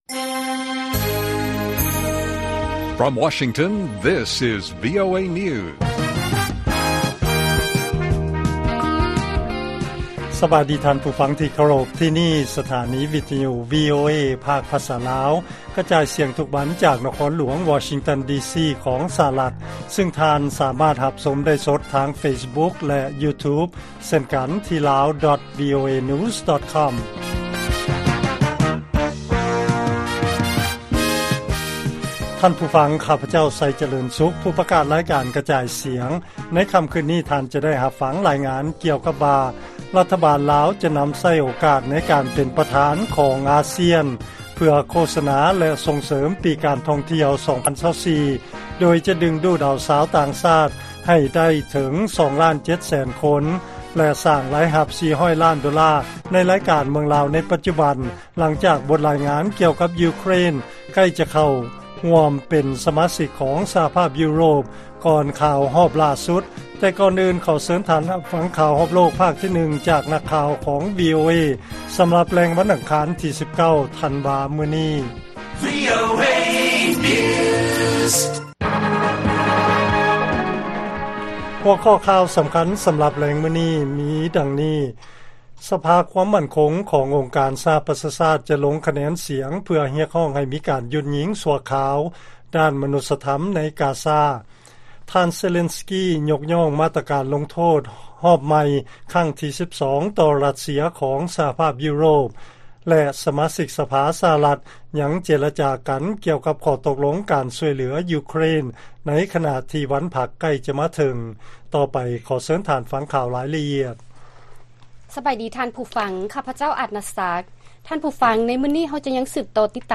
ລາຍການກະຈາຍສຽງຂອງວີໂອເອ ລາວ: ສະພາຄວາມໝັ້ນຄົງ ຂອງອົງການສະຫະປະຊາຊາດ ຈະລົງຄະແນນສຽງ ເພື່ອຮຽກຮ້ອງໃຫ້ມີການຢຸດຍິງຊົ່ວຄາວດ້ານມະນຸດສະທຳ ໃນກາຊາ